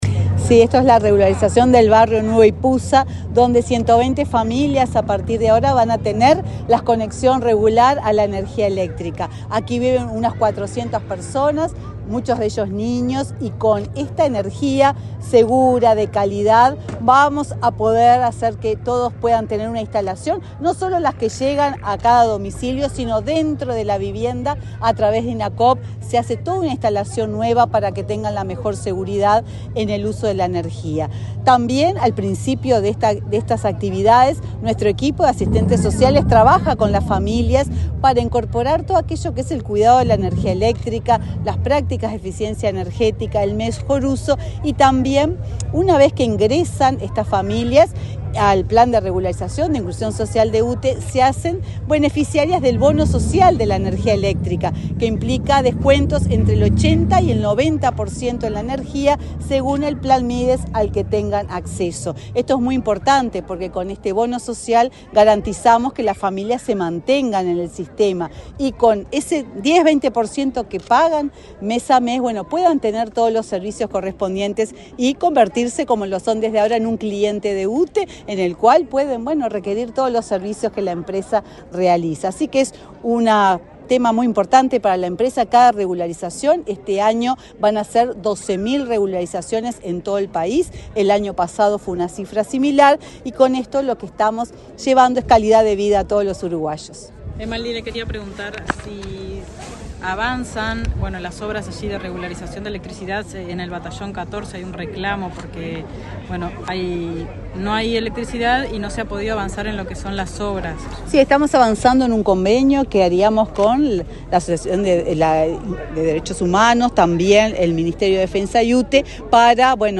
Declaraciones de la presidenta de UTE, Silvia Emaldi
La presidenta de la UTE, Silvia Emaldi, dialogó con la prensa, antes de participar en la inauguración de obras de electrificación en el barrio Nuevo